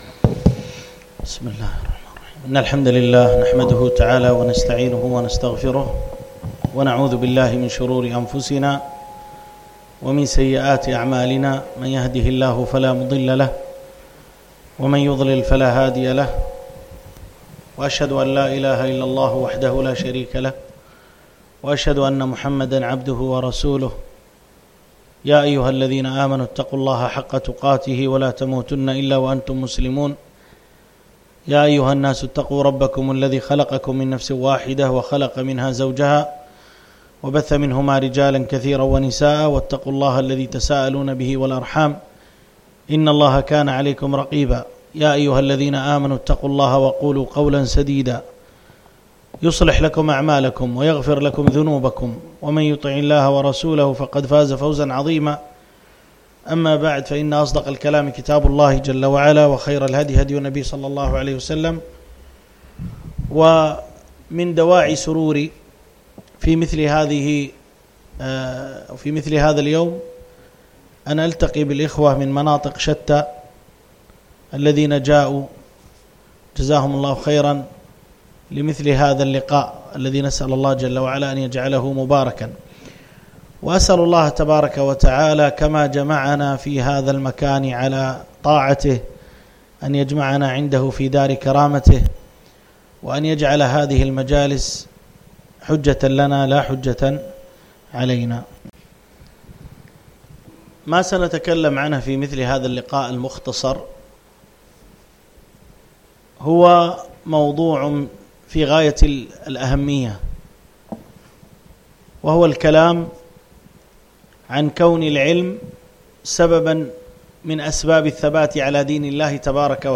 Lectures: Oct 2019 Dawrah in Mumbai – DeenSahih